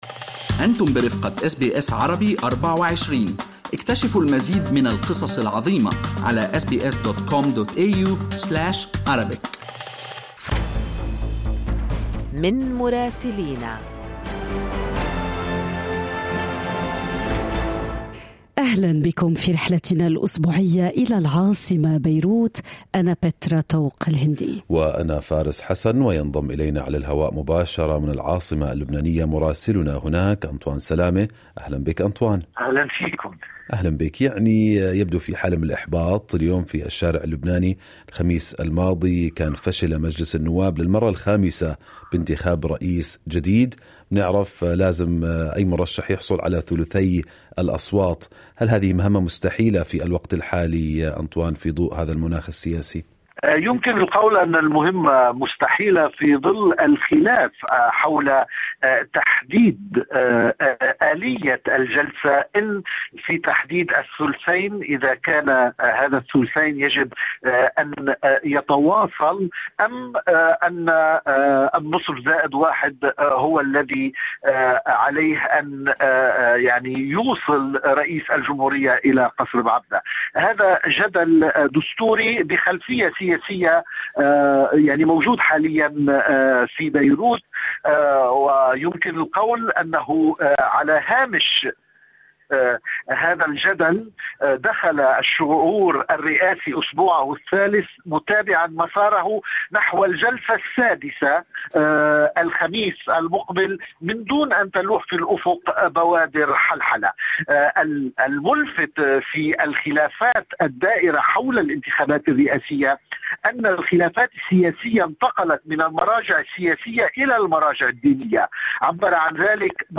يمكنكم الاستماع إلى تقرير مراسلنا في لبنان بالضغط على التسجيل الصوتي أعلاه.